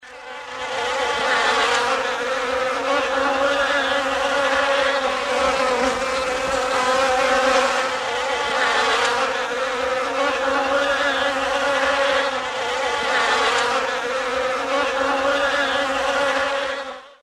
На этой странице собраны разнообразные звуки пчел: от одиночного жужжания до гула целого роя.
Звук жужжания пчелиного роя